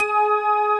B3PLUCKG#4.wav